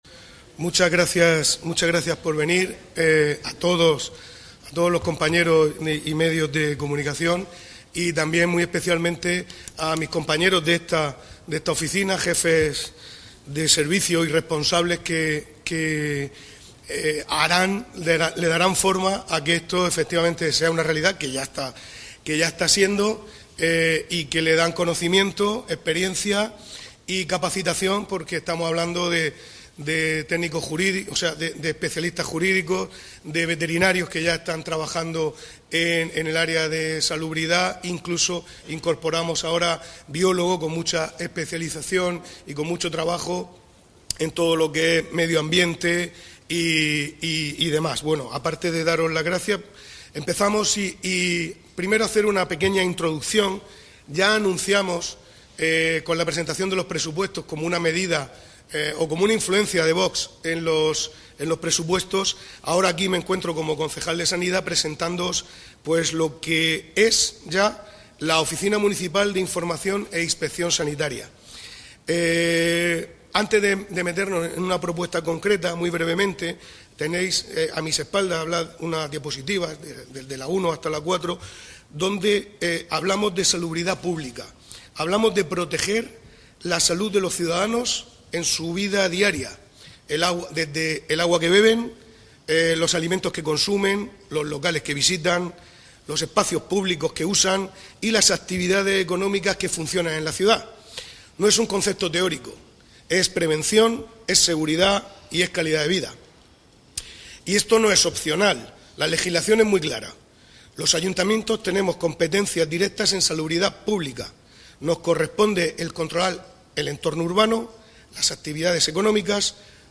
Audio: Declaraciones del edil Gonzalo L�pez.